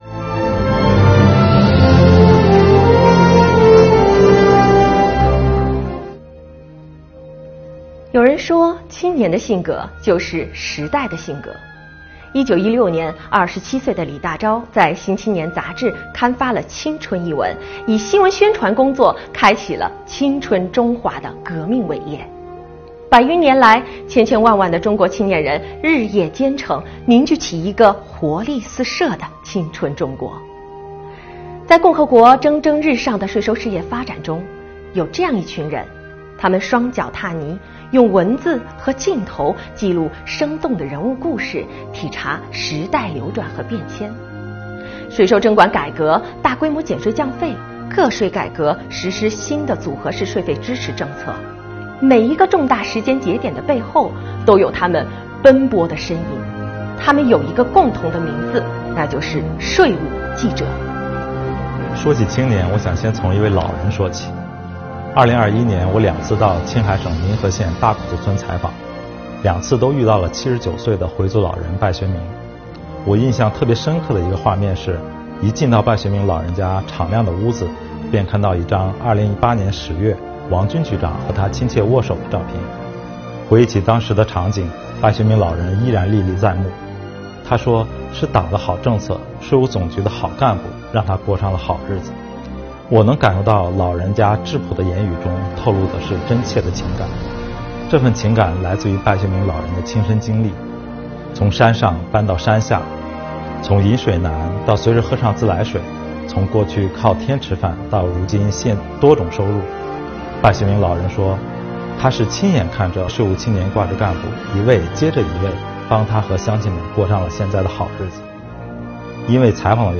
[中国税务报] 税务青年记者深情讲述······
青年记者们不负伟大时代、勇于筑梦追梦的精彩故事，以及他们真挚诚恳、饱含深情的讲述，深深打动了现场观众，并获得税务总局领导的肯定和表扬。